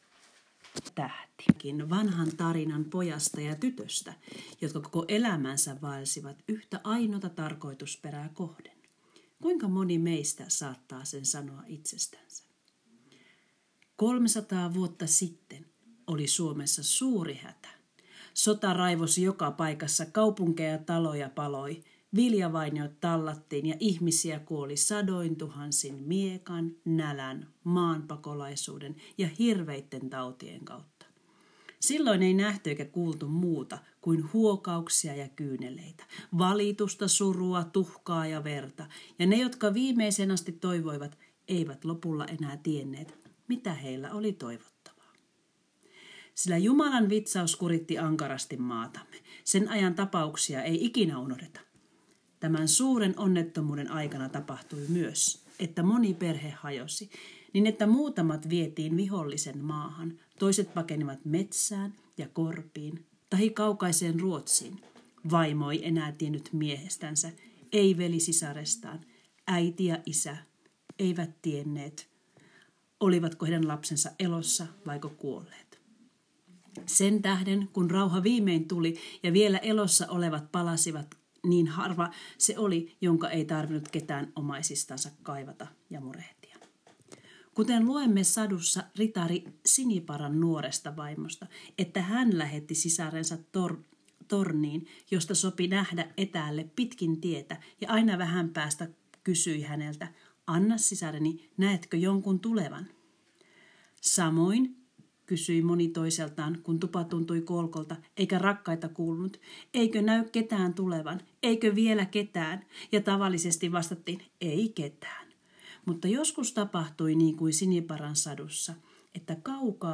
Kuuntele open lukemana Zacharias Topeliuksen satu "Koivu ja tähti". Pahoittelen taustalta kuuluvia ääniä ja lyhyitä keskeytyksiä.